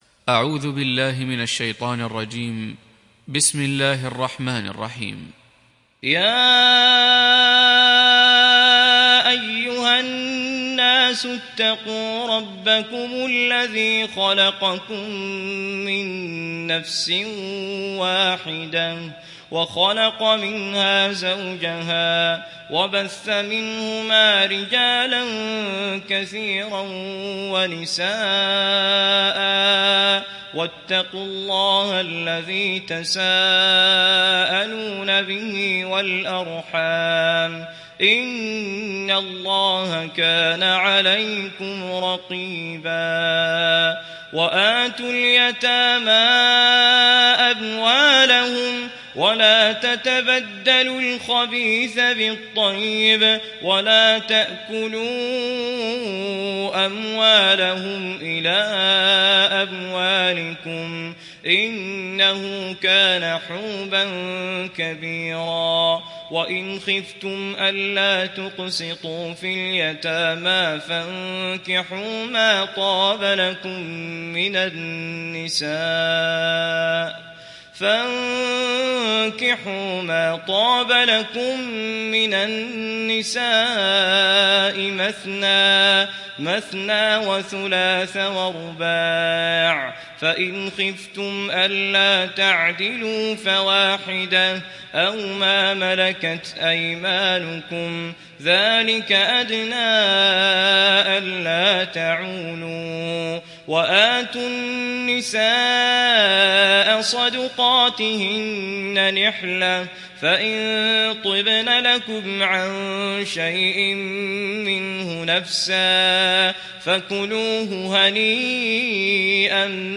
(Riwayat Hafs)